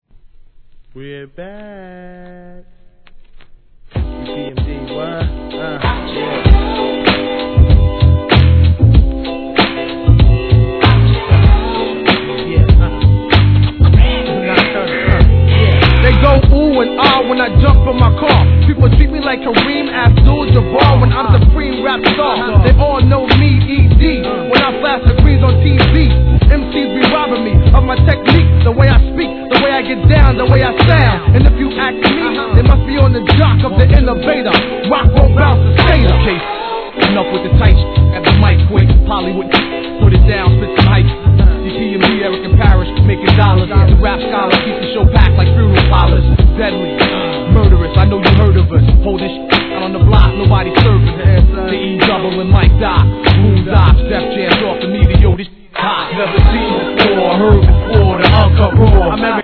HIP HOP/R&B
相変わらずのFUNKサウンドのオリジナルVER.に、B/W 心地よ～いネタ感溢れるREMIX収録!!